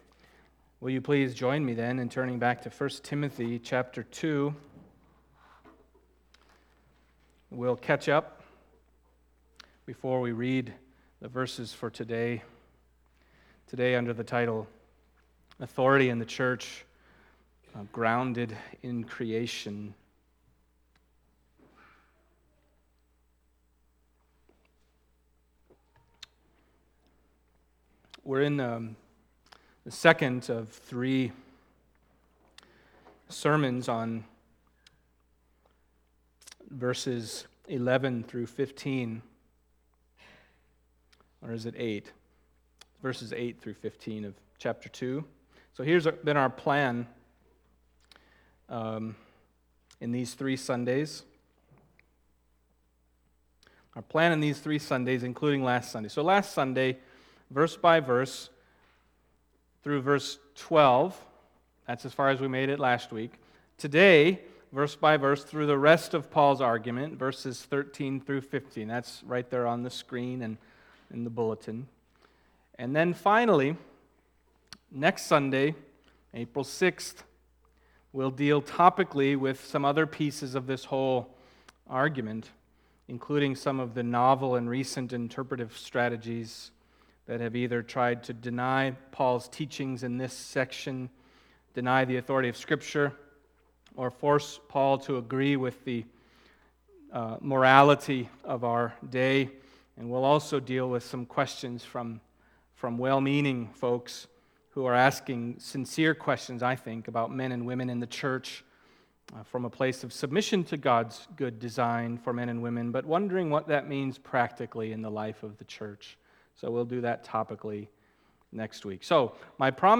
1 Timothy 2:13-15 Service Type: Sunday Morning 1 Timothy 2:13-15 « Women in the Church